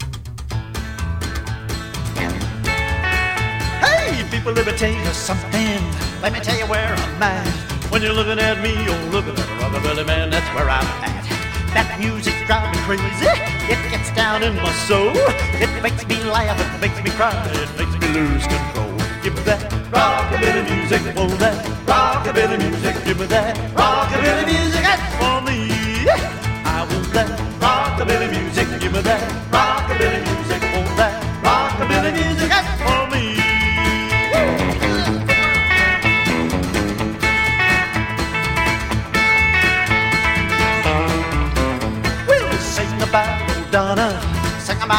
真っ白なウッドベースがトレードマークで、ここでは加えてギターとドブロ、スティールギターを演奏。
Rock'N'Roll, Rockabilly　Finland　12inchレコード　33rpm　Stereo